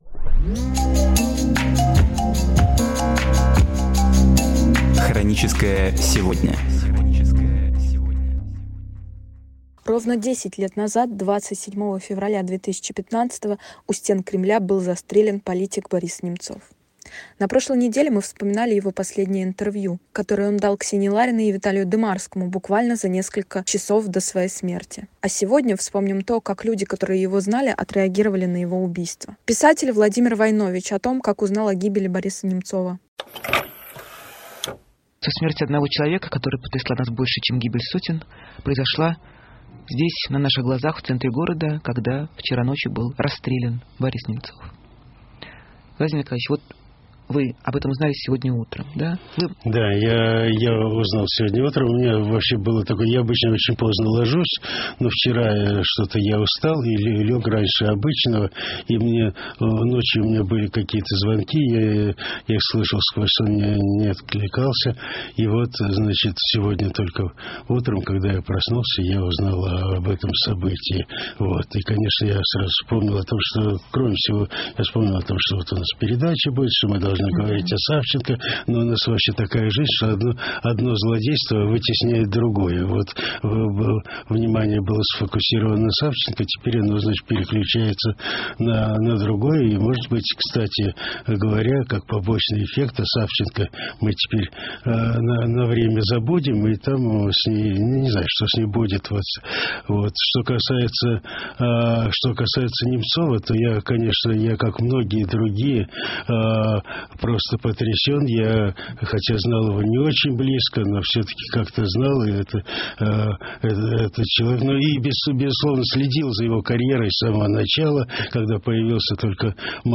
Архивные передачи «Эха Москвы» на самые важные темы дня сегодняшнего